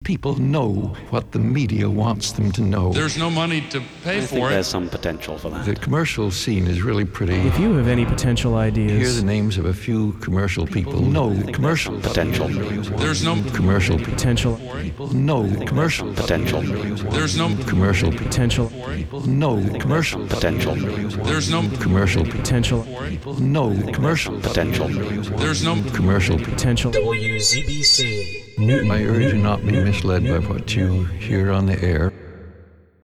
Don't be mislead Legal ID